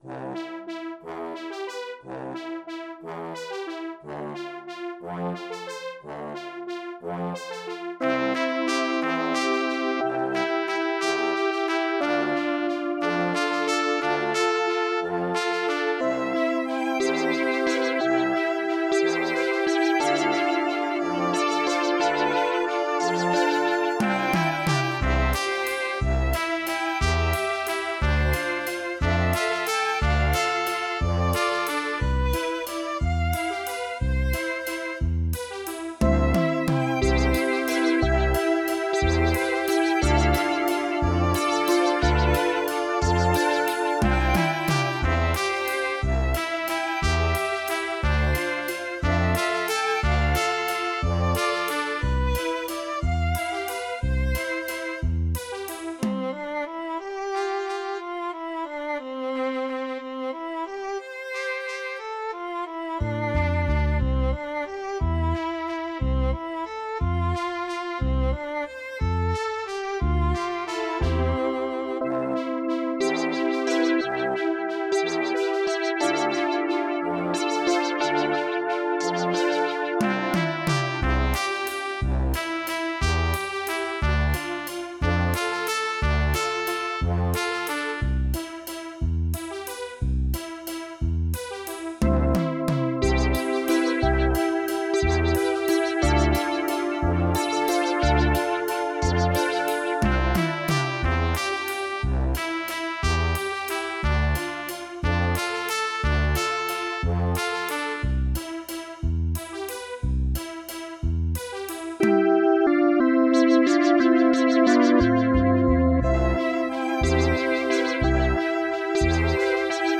с помощью компьютера и синтезатора
Минусовка (для караоке)